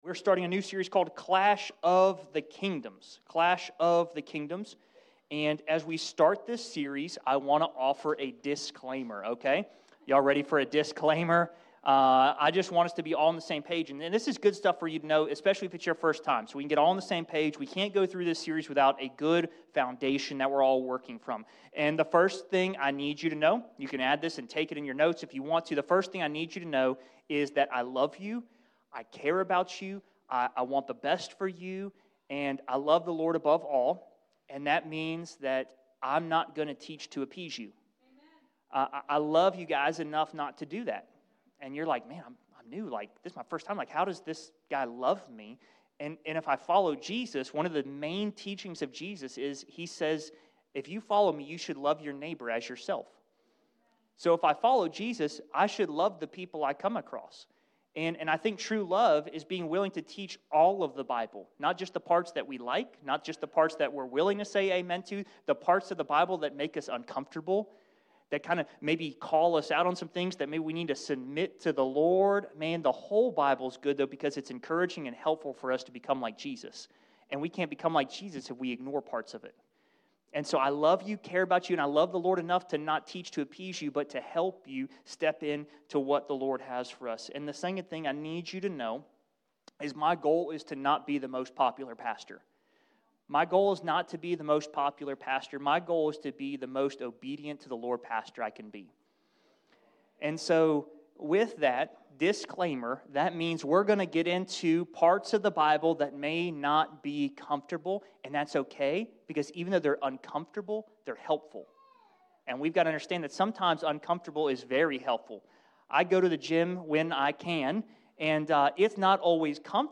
In today's message, we discuss how we all serve something or someone, whether it’s family, social media, our job, etc. But let’s live our lives first for Jesus, the only one worth it and the only one who gives true purpose!